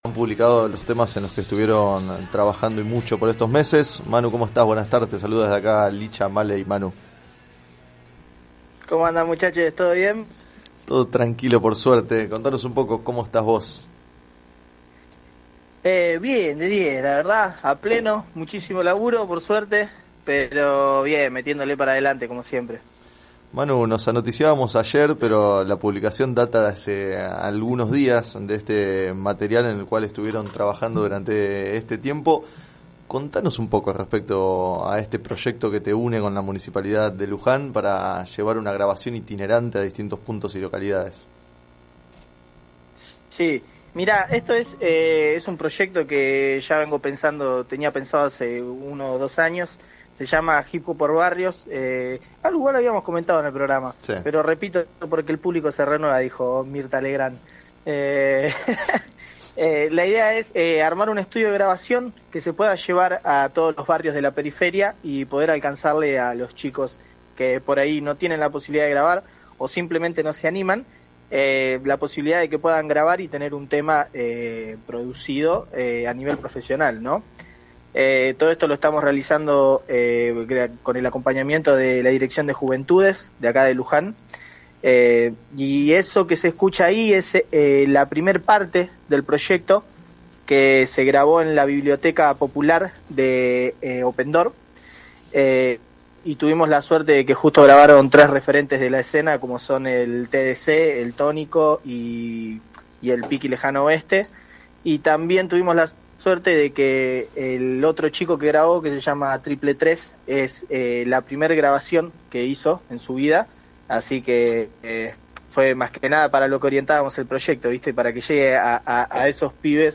En declaraciones al programa Sobre las cartas la mesa de FM Líder 97.7